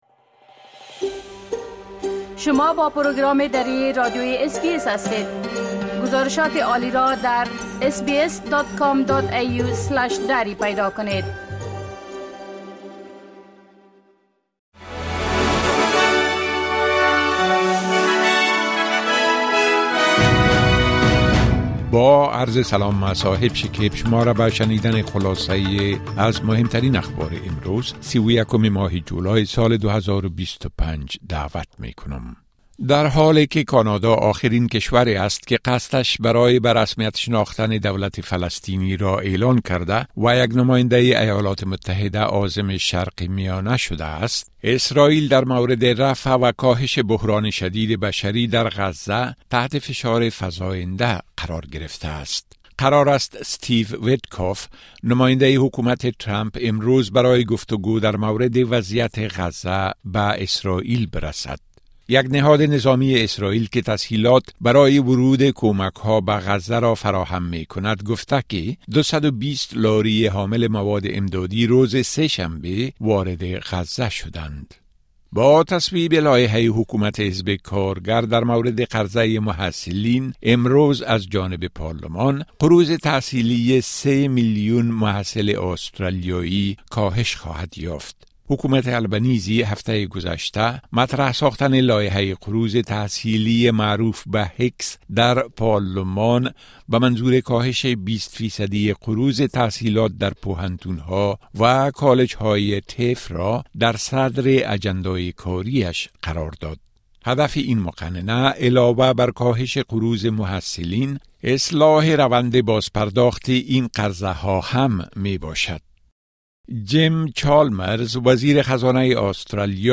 خلاصۀ مهمترين خبرهای روز از بخش درى راديوى اس‌بى‌اس